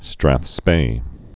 (străthspā, străthspā)